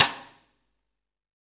SNR JAZZ 05L.wav